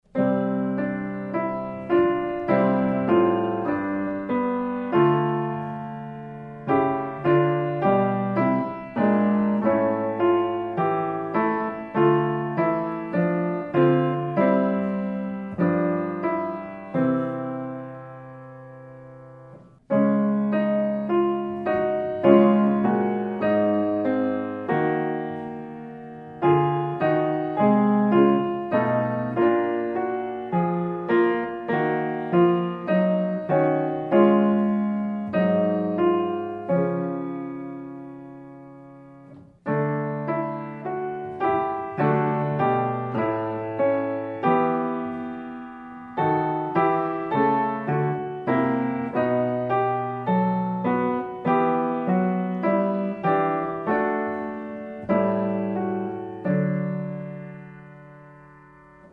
mit der Klavierbegleitung zu